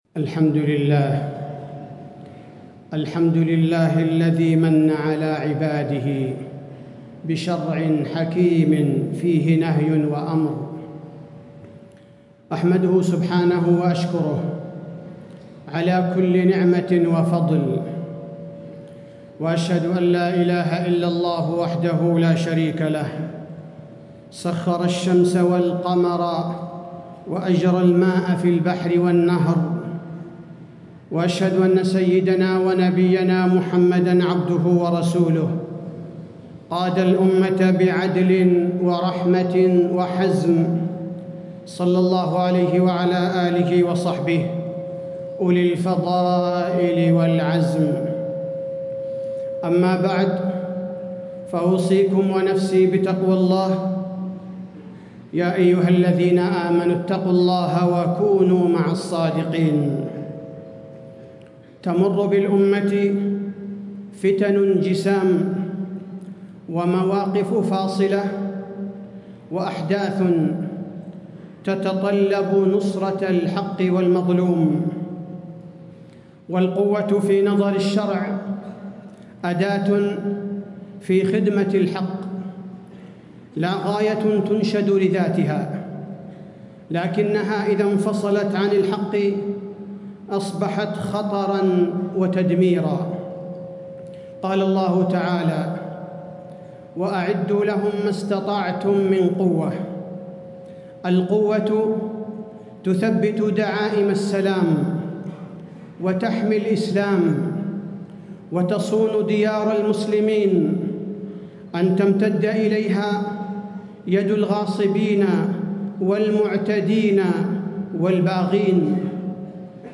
تاريخ النشر ٢١ جمادى الآخرة ١٤٣٦ هـ المكان: المسجد النبوي الشيخ: فضيلة الشيخ عبدالباري الثبيتي فضيلة الشيخ عبدالباري الثبيتي القوة الحازمة واليمن